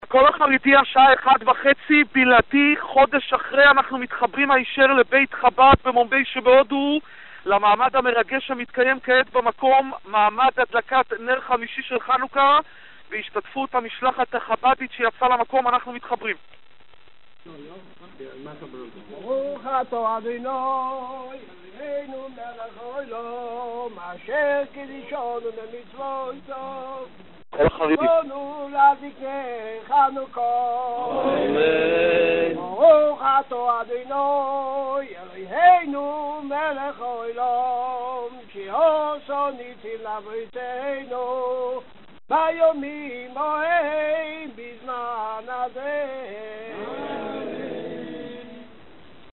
מעמד מרגש של הדלקת נר החנוכה נערך הערב (חמישי) בחזית בית-חב"ד ההרוס במומביי שבהודו. בני המשפחה, לצד ידידים, רבנים ושלוחים, עמדו זה לצד זה ושרו את "הנרות הללו"  לוידאו בלעדי מההדלקה  ● בפנים: הקלטות בלעדיות של "הקול החרדי" ממעמד ההדלקה >>>